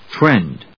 /trénd(米国英語), trend(英国英語)/